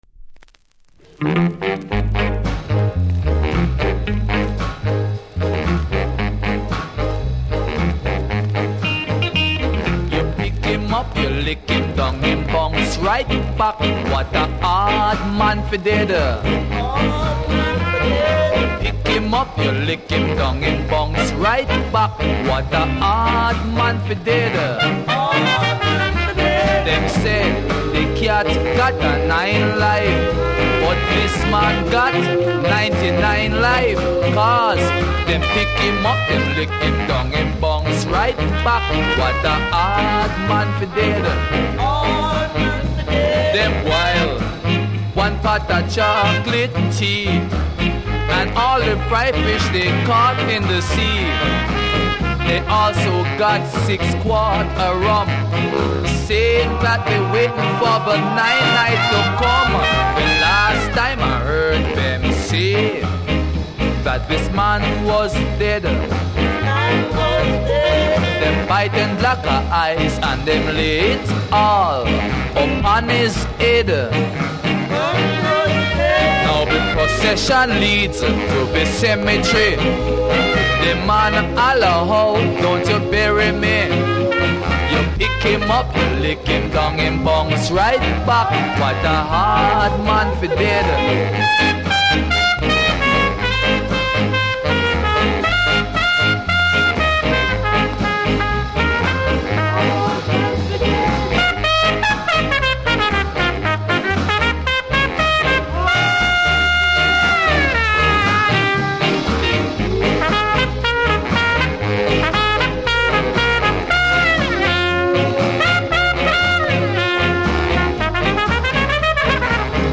数本深いキズありますがノイズはそれほど感じなくプレイはOKレベルだと思いますので試聴で確認下さい。
両面出だしの無録音部分は音がないのでノイズ感じます。